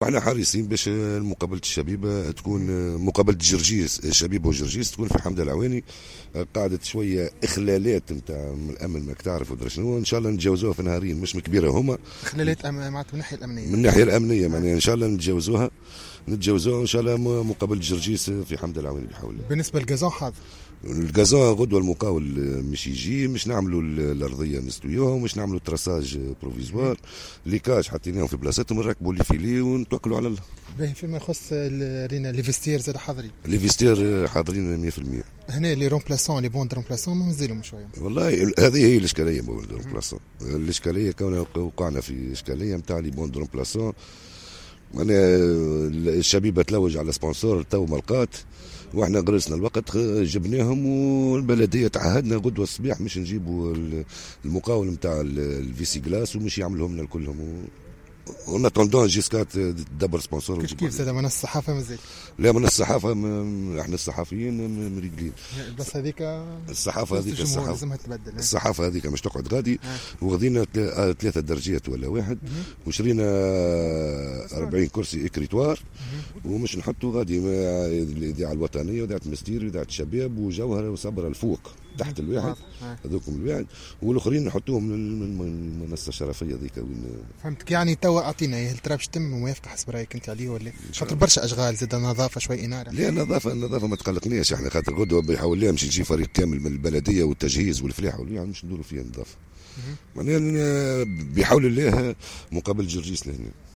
الحوار التالي